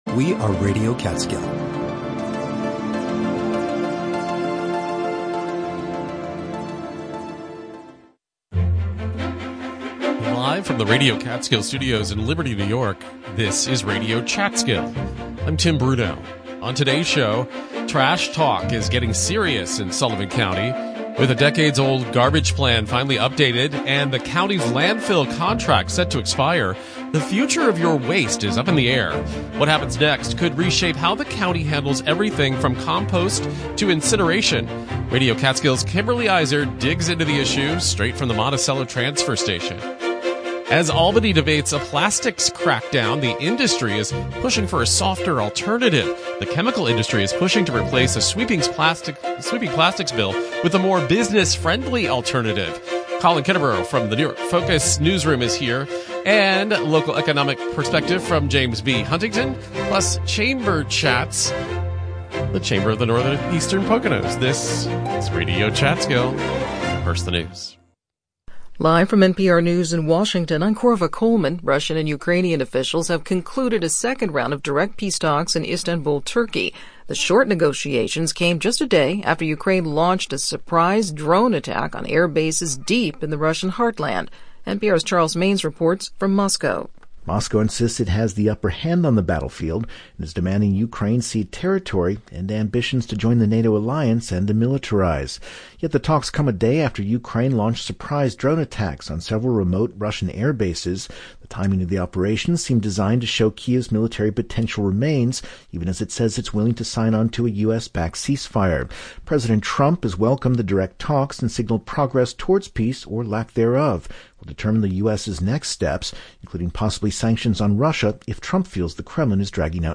Latest was The History of Witchcraft In Cheshire - Live from The Grange Theatre.